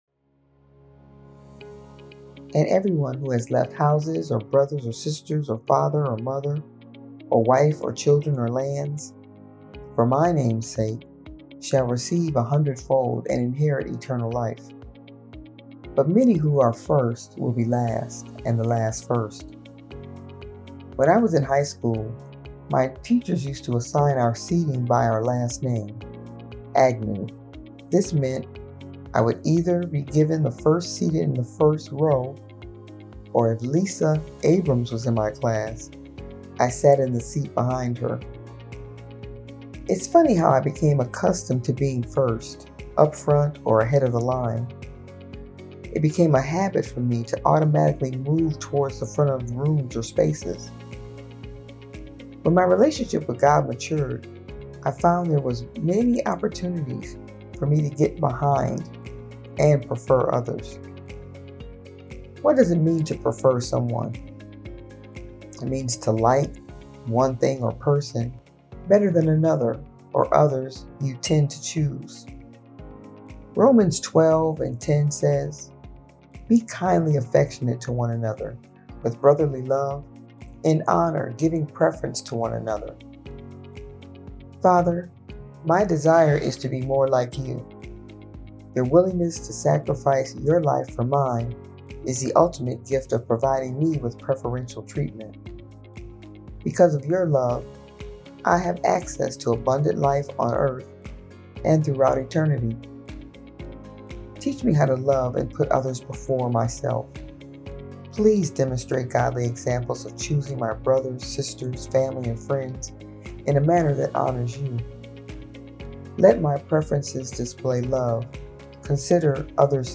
She shares words of inspiration, encouragement, and prayer.